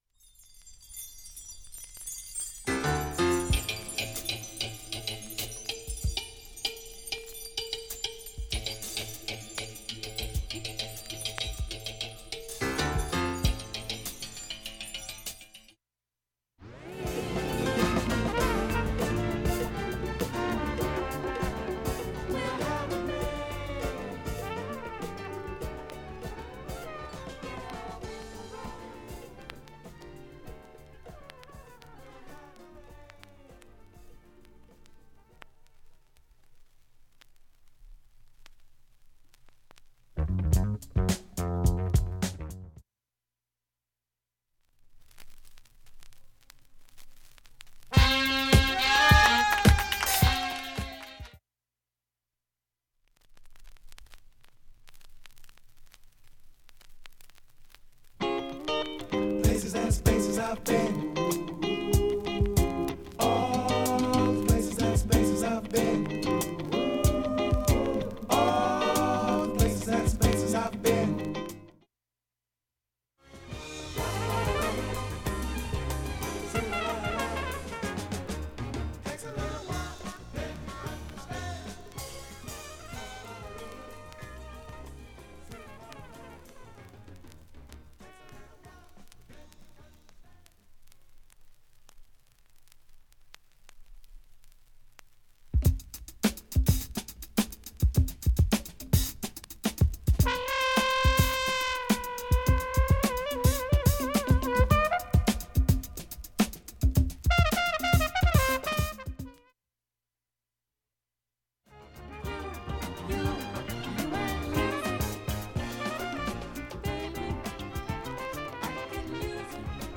盤面きれいで音質良好全曲試聴済み。
A-2始めにかすかなプツが２回出ます。
無音部のチリも全く問題ないですが
現物の試聴（上記録音時間２分半）できます。音質目安にどうぞ